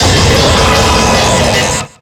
Cri de Regigigas dans Pokémon X et Y.